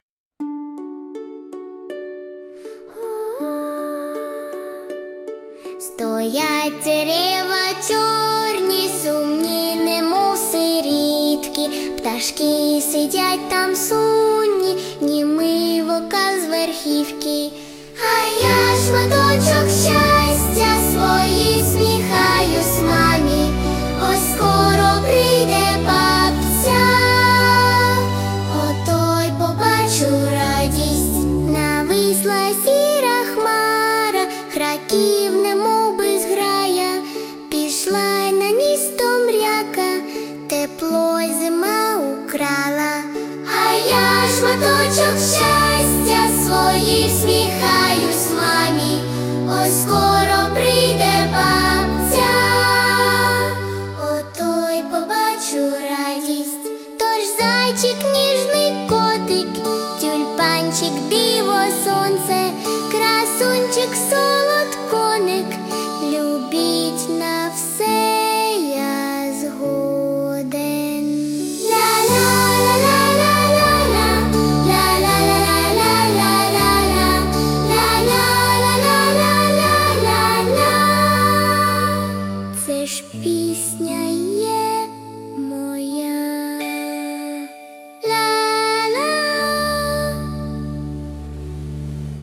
Музична композиція створена за допомогою SUNO AI
СТИЛЬОВІ ЖАНРИ: Ліричний
Приємно вразили і порадували дитячою пісенькою і співом.